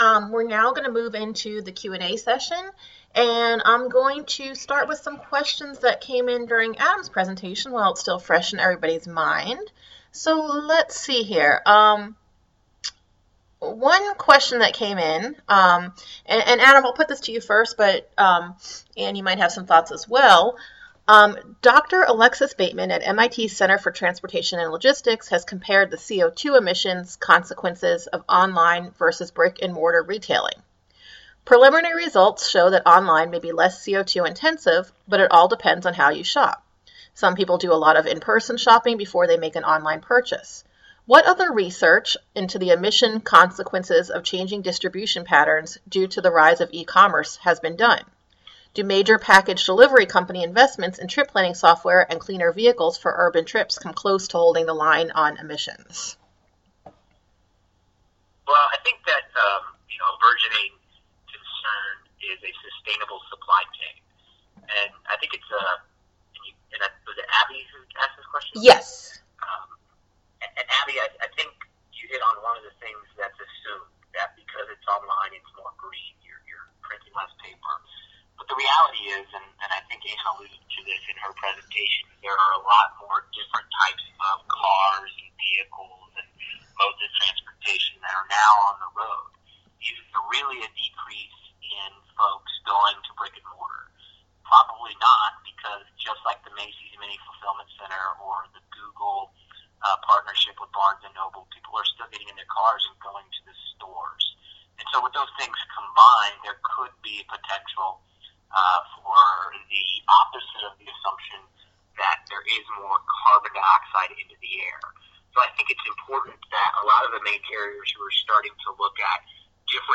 Questions & Answers